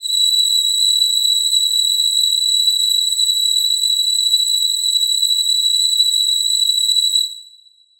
Choir Piano
B7.wav